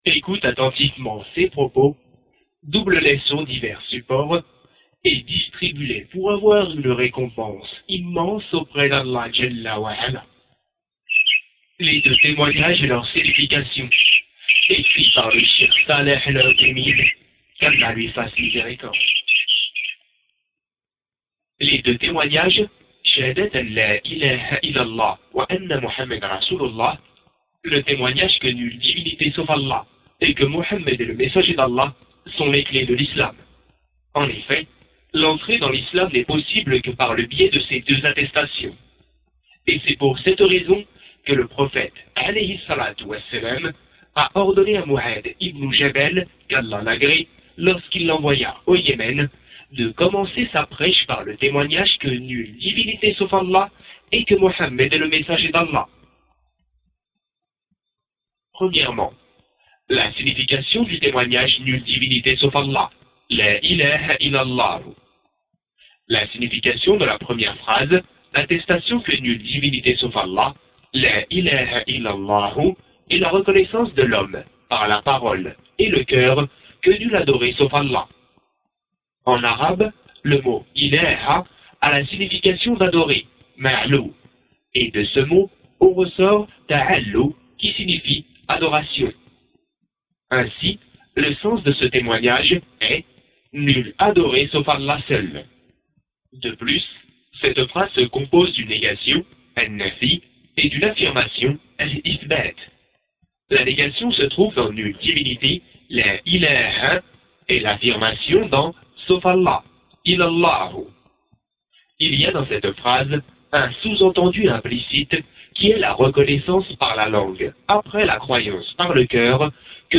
Livre audio : Les deux témoignages et leurs significations - Français - Mohammed ibn Othaymine